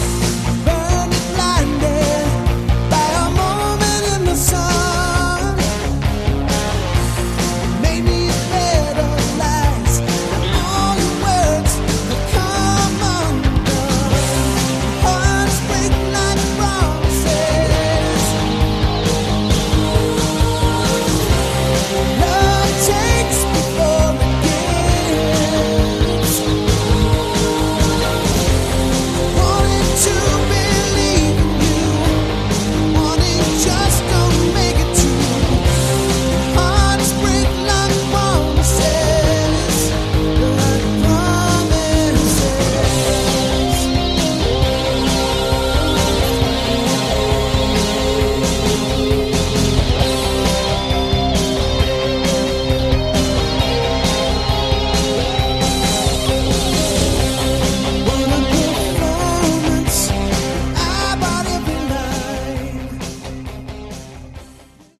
Category: Lite Aor